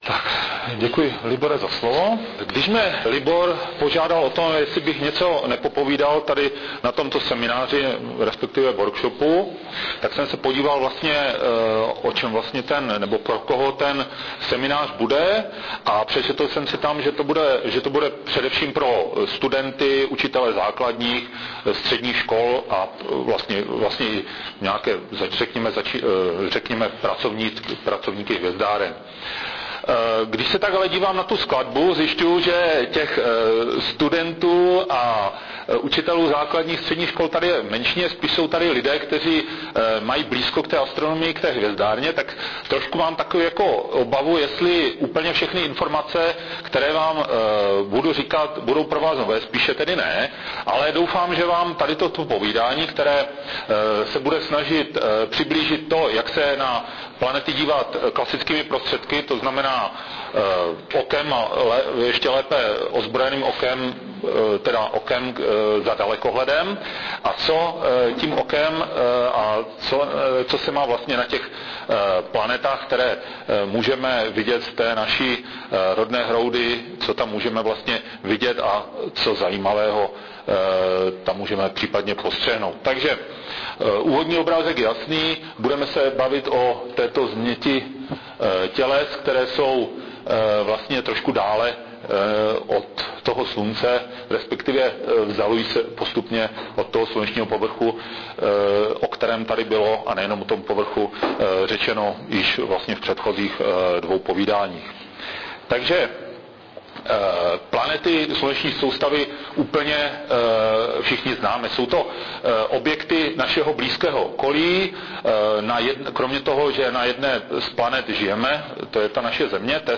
Materiály z workshopu o významu a úloze astronomických pozorování | Vzdělávací materiály | Výstupy projektu | Obloha na dlani | Hvězdárna Valašské Meziříčí